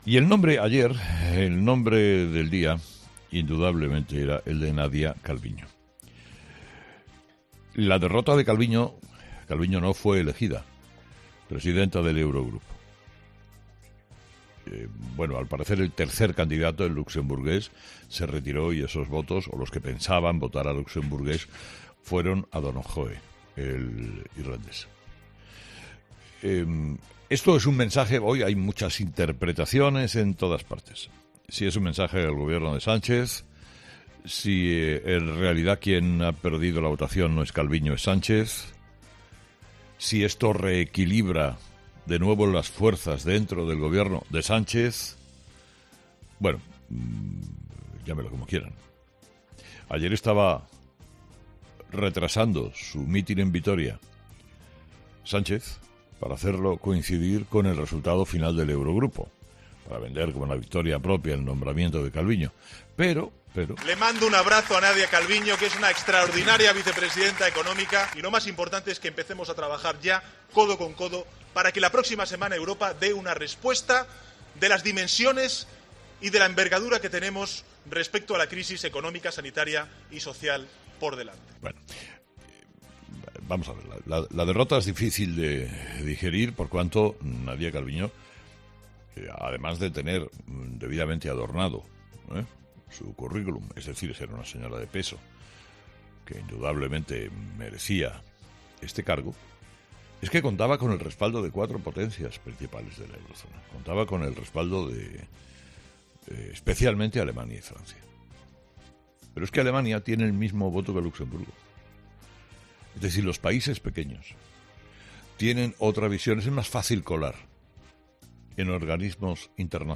Carlos Herrera, director y presentador de 'Herrera en COPE', comenzó el programa del viernes 10 de julio de 2020 analizando cómo nos ha cambiado la vida respecto hace un año, recordando cómo han sido los últimos cierres de temporada con todo el equipo de 'Herrera en COPE'.